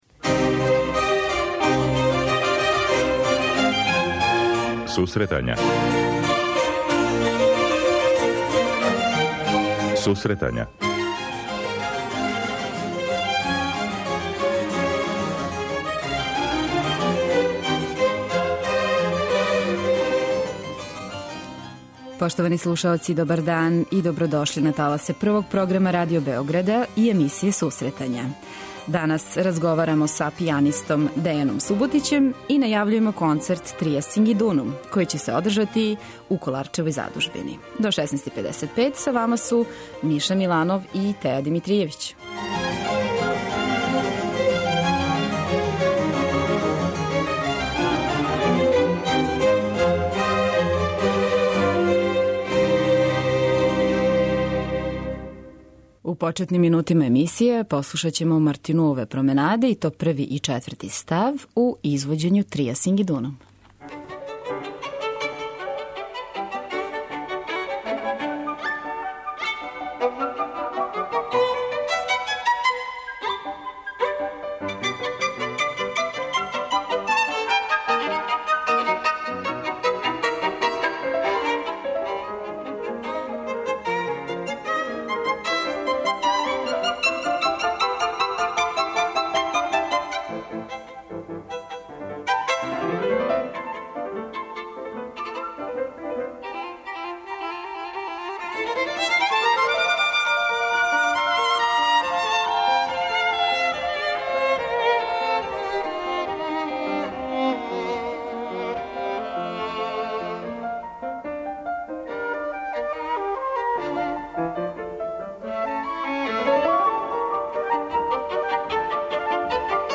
преузми : 26.19 MB Сусретања Autor: Музичка редакција Емисија за оне који воле уметничку музику.